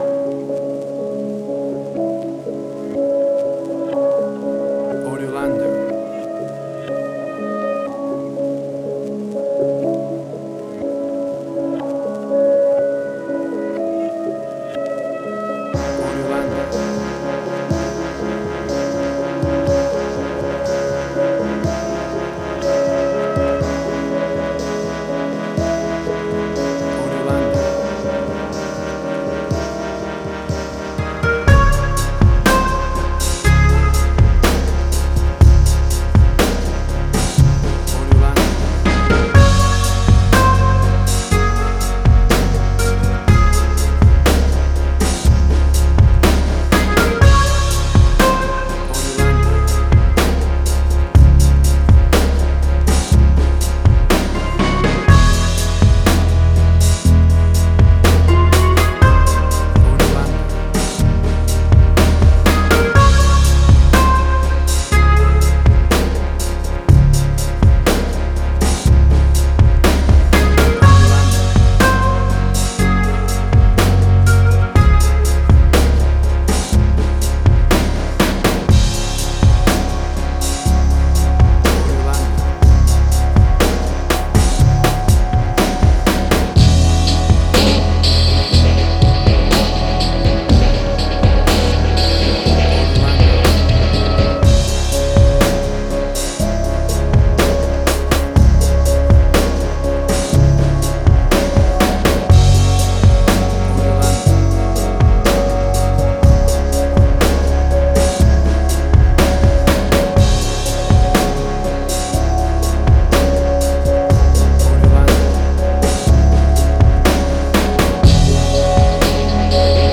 Chill Out.
Tempo (BPM): 61